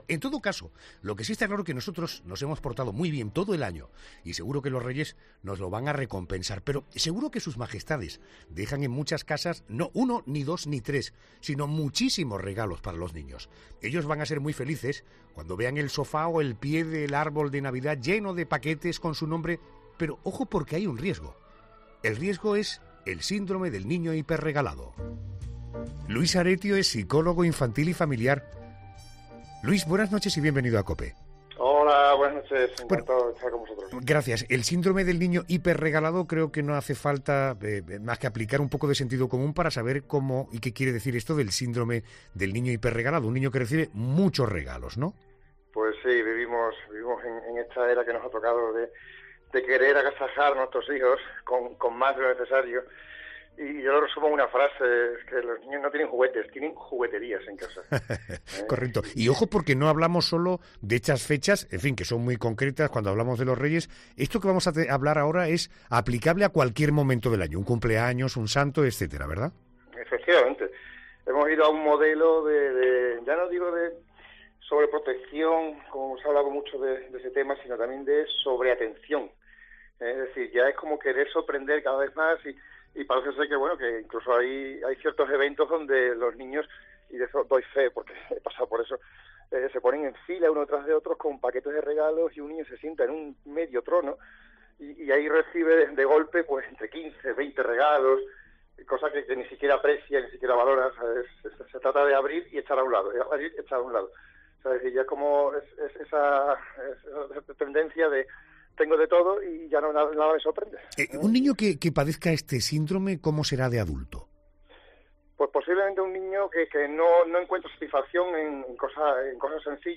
En 'La Noche de COPE' hablamos con un psicólogo infantil y familiar sobre el síndrome del niño hiperregalado.